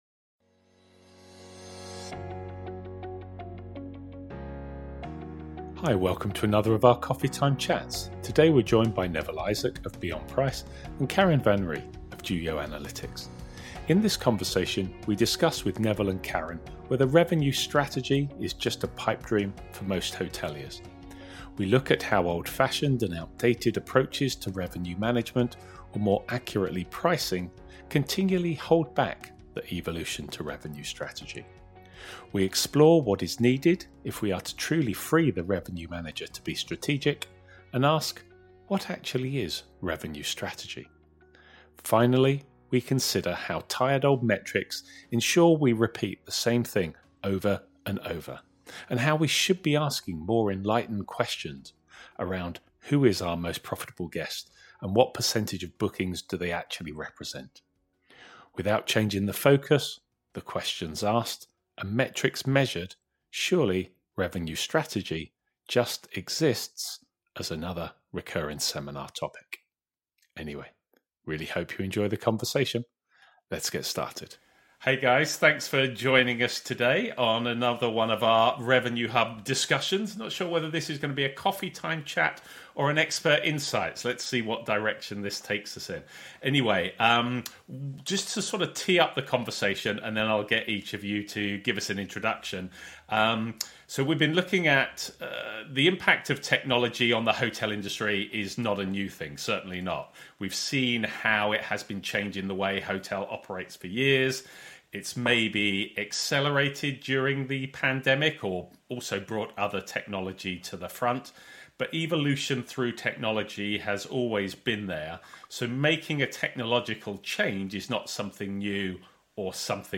Welcome to another of our Coffee Time chats.